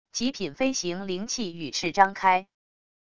极品飞行灵器羽翅张开wav音频